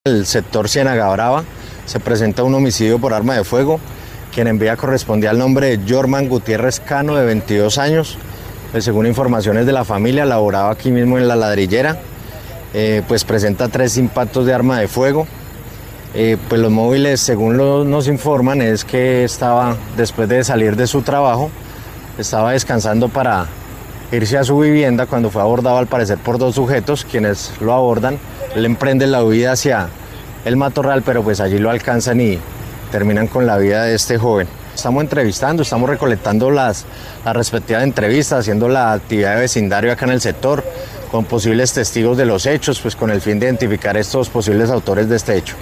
Coronel Ángel Vivas, comandante Policía del Magdalena Medio